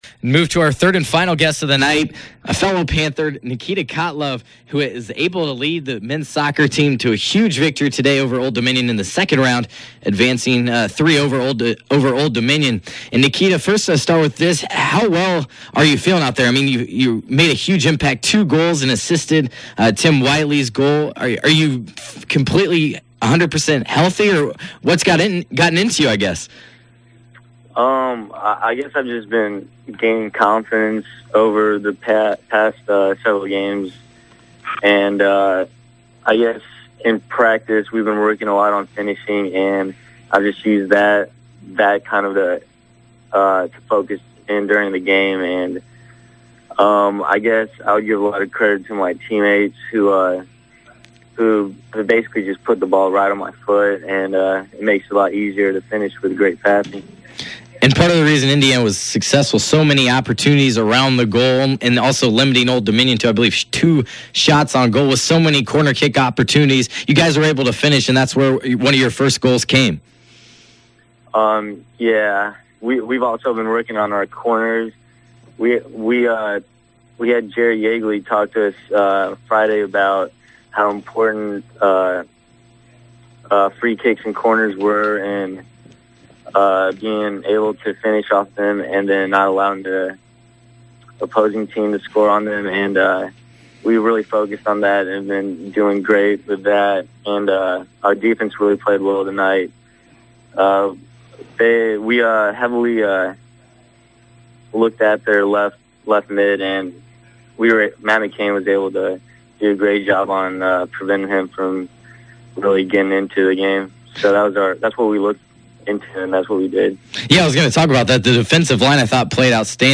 On my weekly radio show, I was joined by three unique and special guests to talk about hot topics.